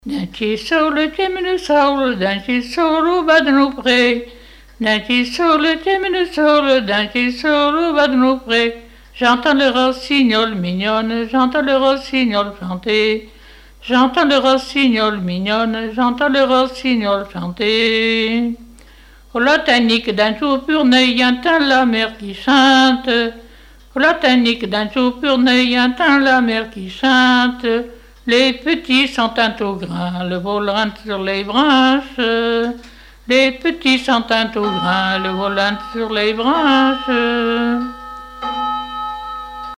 Couplets à danser
chanteur(s), chant, chanson, chansonnette
branle : courante, maraîchine
Pièce musicale inédite